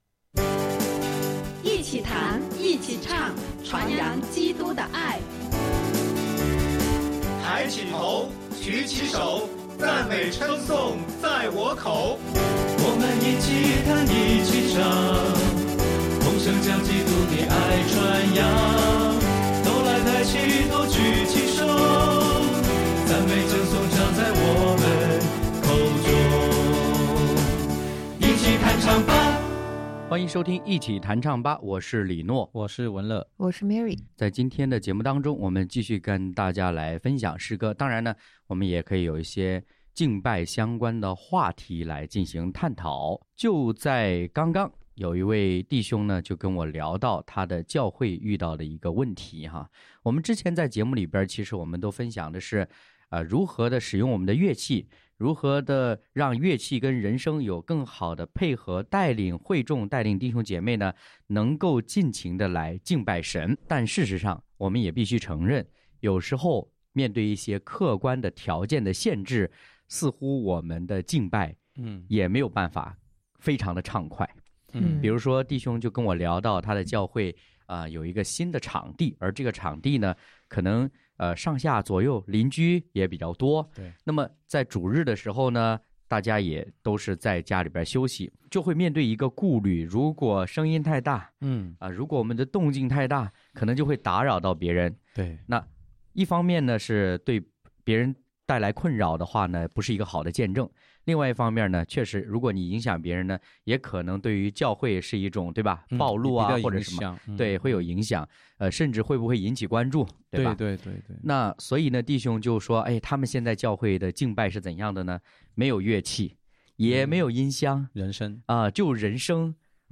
敬拜分享：高声默语，皆是赞美；诗歌：《如果我能唱》、《爱，我愿意》